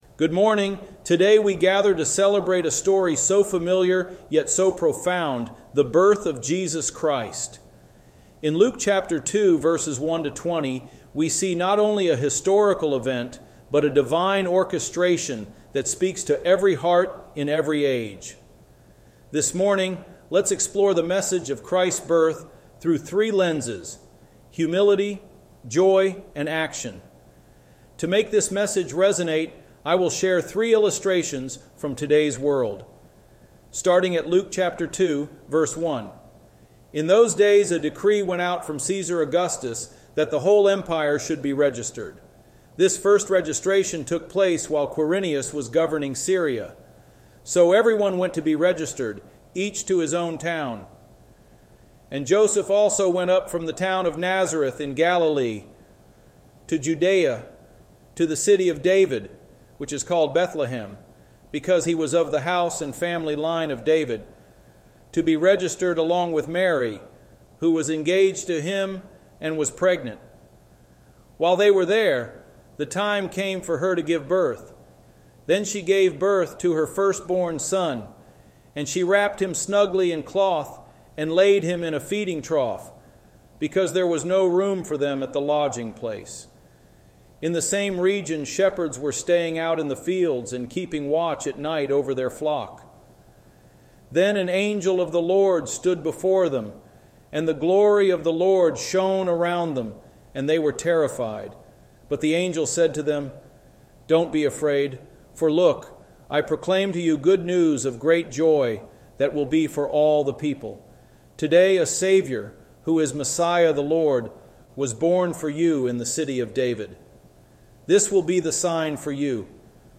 Our Christmas 2024 message.